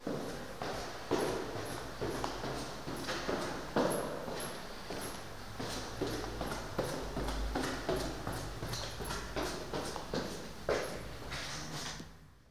Index of /90_sSampleCDs/Propeller Island - Cathedral Organ/Partition N/DOORS+STEPS
CH.-STEPS -R.wav